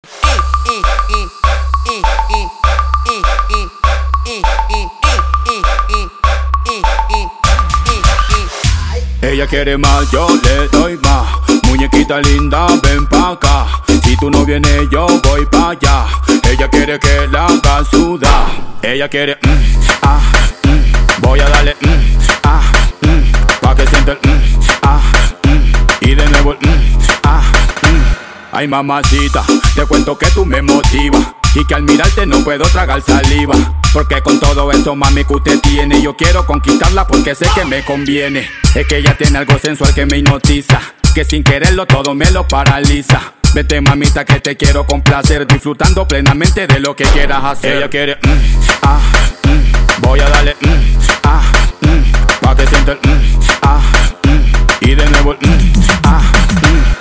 • Качество: 320, Stereo
ритмичные
мужской вокал
рэп
Хип-хоп
dance
latino
речитатив